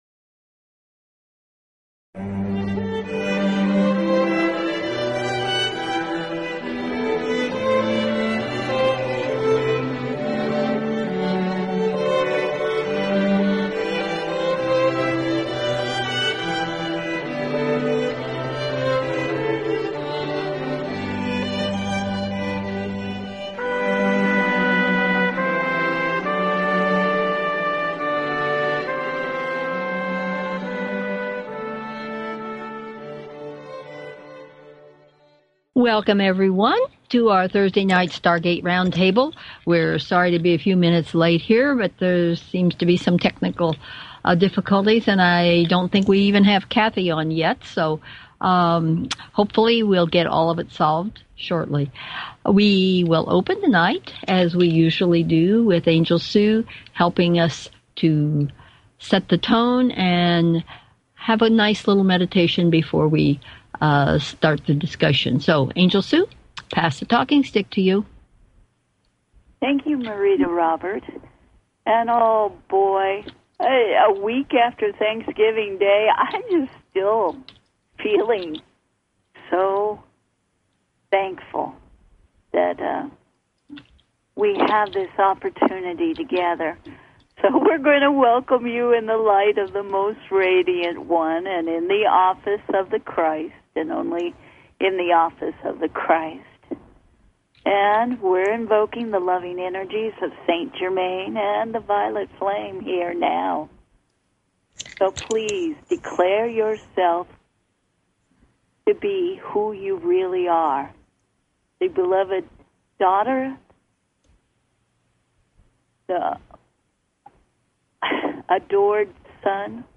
Talk Show Episode
After an opening meditation, we begin with a brief overview of "Breaking News" and also offer a segment for you to get your questions answered.